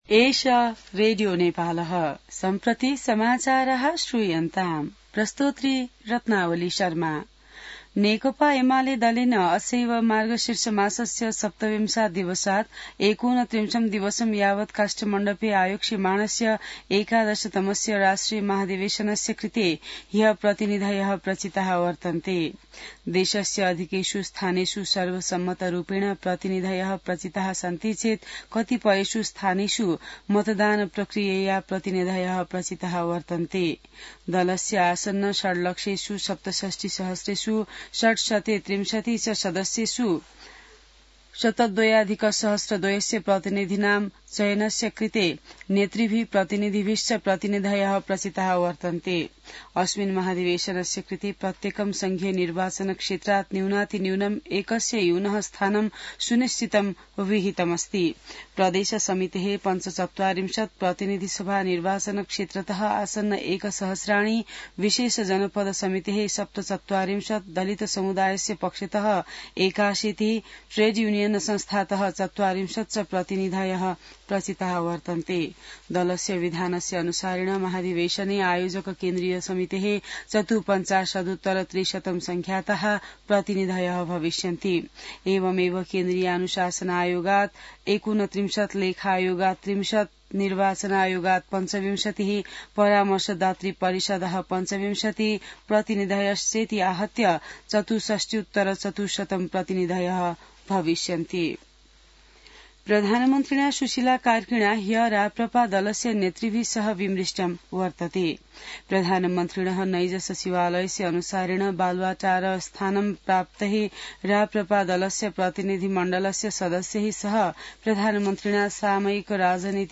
संस्कृत समाचार : १४ मंसिर , २०८२